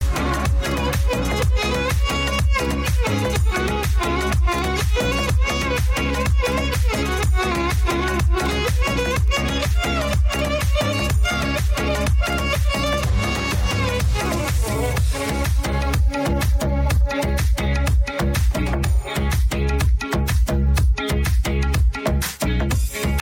حذف صدای خواننده با هوش مصنوعی myedit